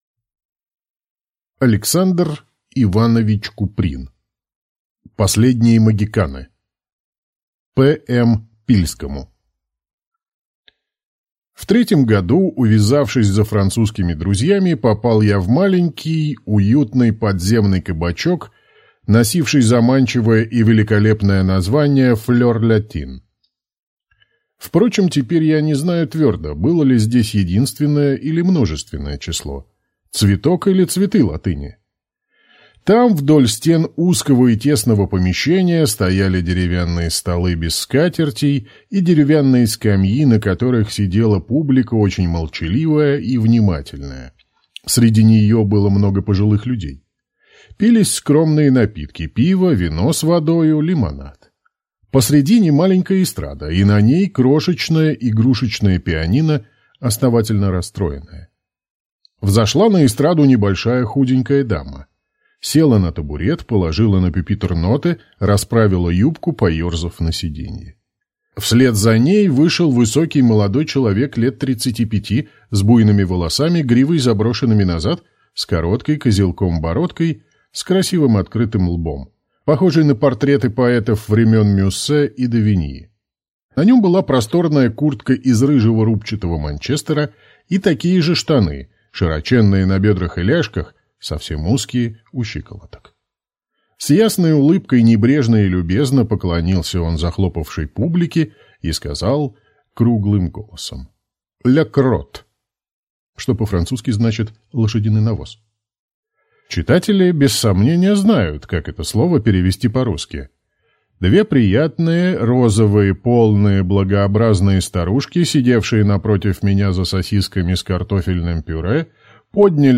Аудиокнига Последние могиканы | Библиотека аудиокниг